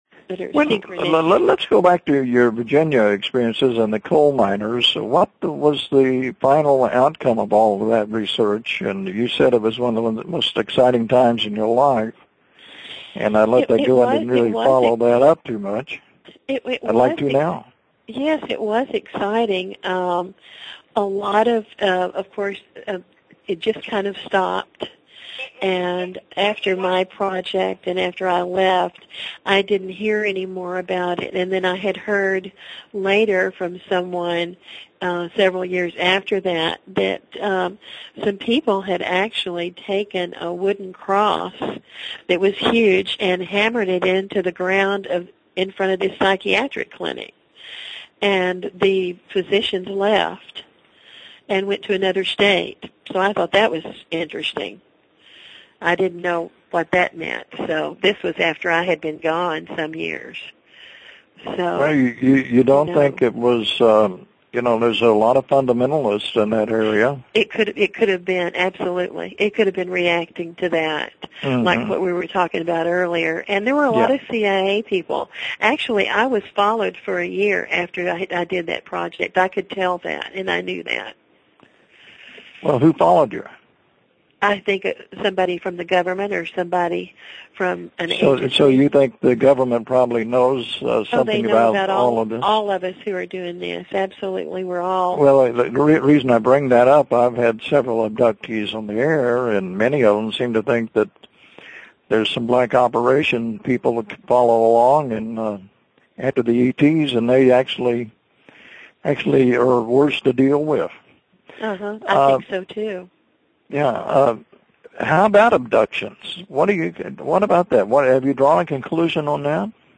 (This interview recorded March 2012)